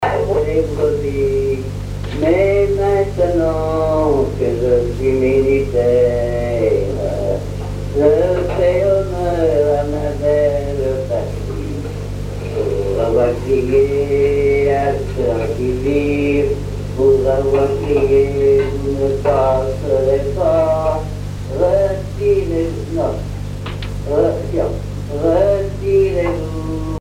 Genre strophique
Chansons populaires
Pièce musicale inédite